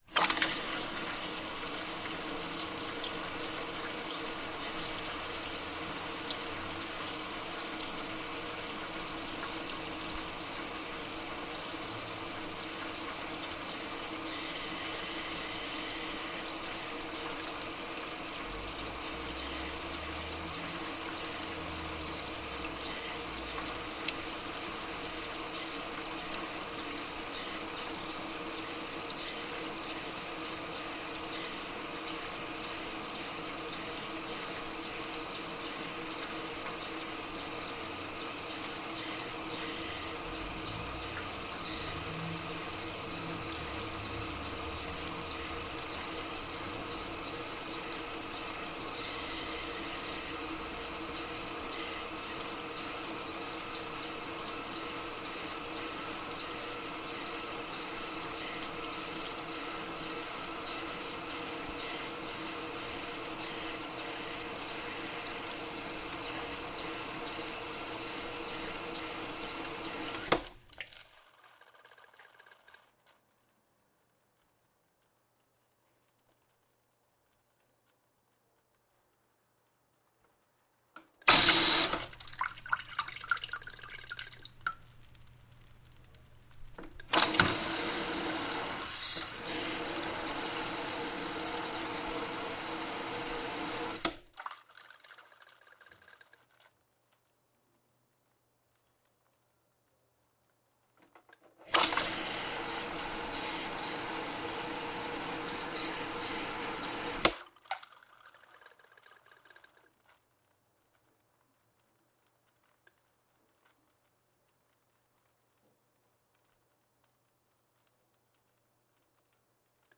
水龙头流出的水
描述：一个水龙头的流出物
标签： 抽头 运行
声道立体声